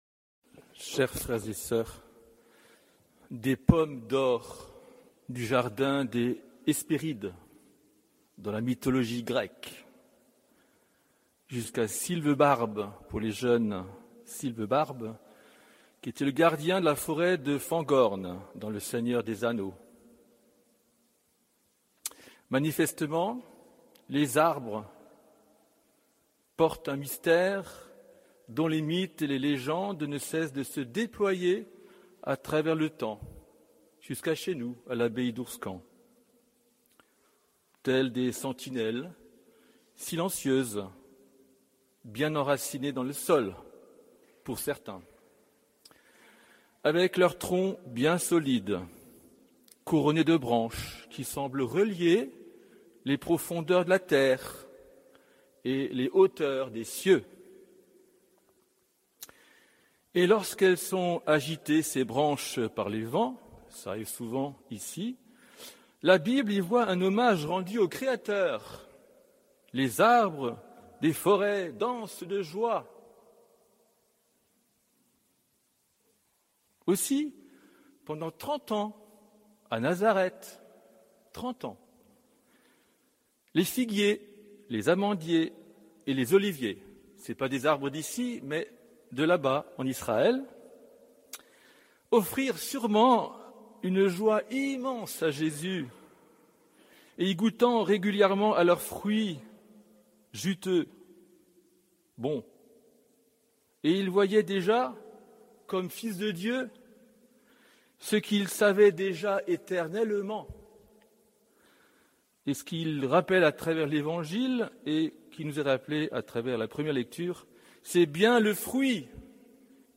Homélie du 8e dimanche du Temps Ordinaire